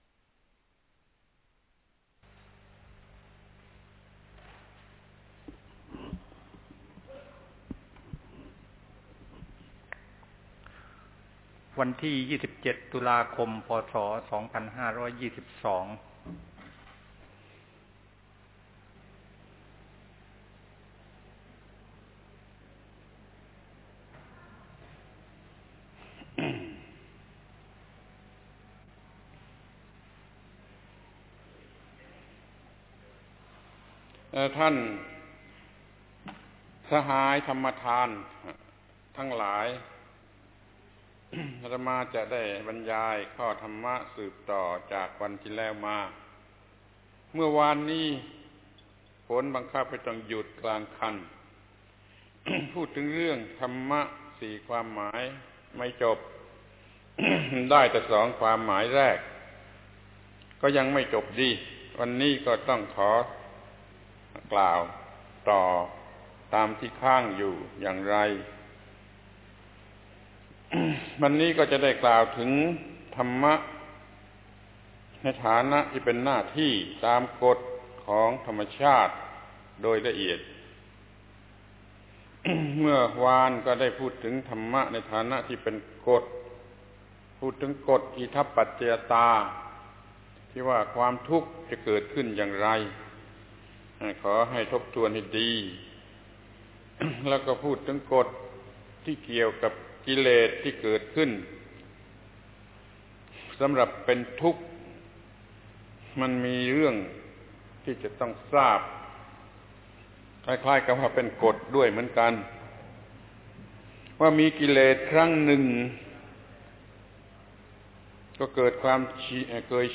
ธรรมบรรยายแก่คณะ ดร.ระวี ภาวิไล ปี 2522 ครั้งที่ 3 ธรรมะคือธรรมชาติโดย 4 ความหมาย (ต่อ)